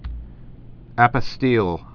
(ăpə-stēl)